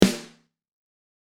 there are two versions, on is dry and the other one is with a room sample blended in for extra KSSSCHHHH.
RF A Rim
It's a Sensitone Elite Custom Alloy snare with a powerstroke head btw.